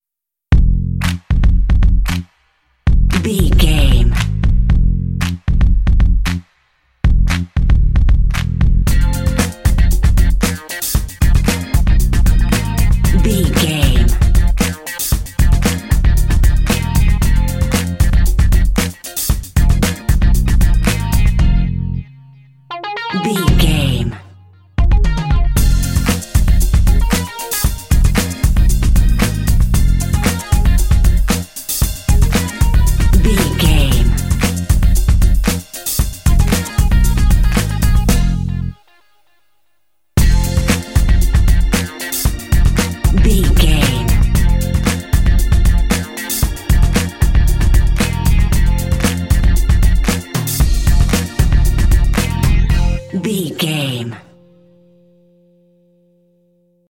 This funky track is full of rhythm and cool synth leaks.
Uplifting
Aeolian/Minor
happy
bouncy
groovy
drum machine
electric guitar
synthesiser
r& b